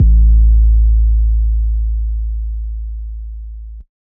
Carbon 808.wav